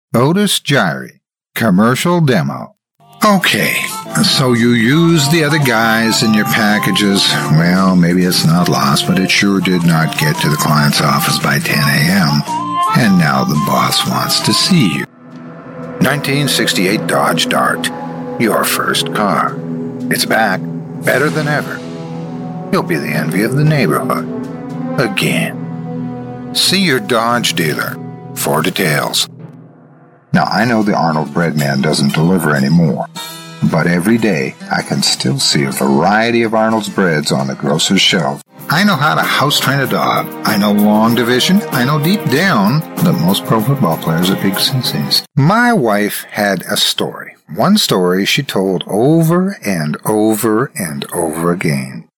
middle west
Sprechprobe: Werbung (Muttersprache):